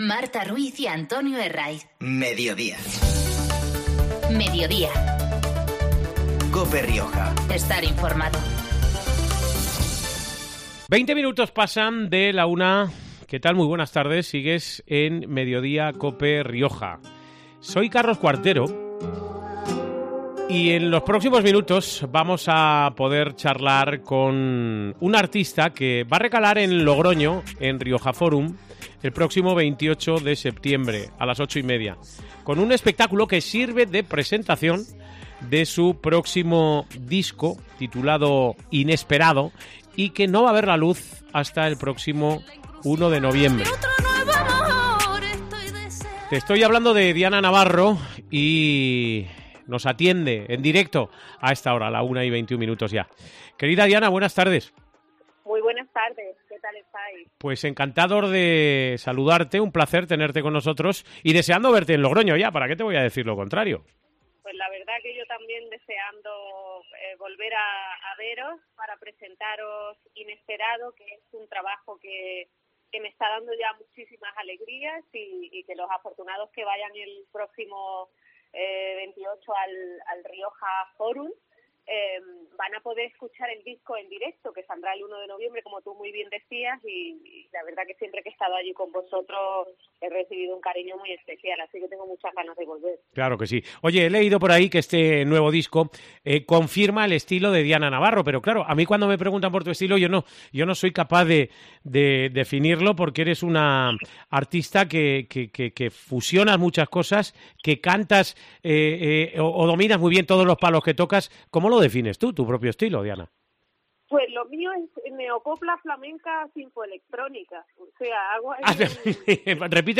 Mediodía en Cope Rioja (miércoles, 18 septiembre. 13:20-13:30 horas) Hoy con la cantante malagueña Diana Navarro.
Este mediodía hemos podido hablar con ella en Cope Rioja.